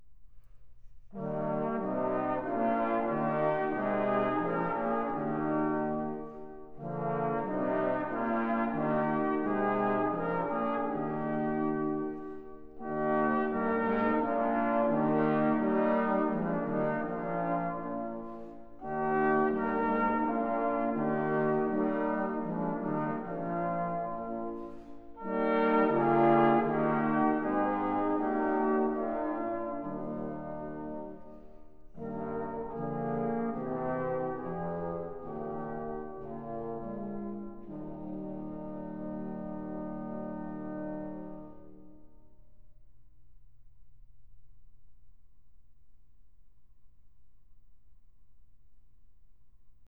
Here is an example of a Trombone Choir in action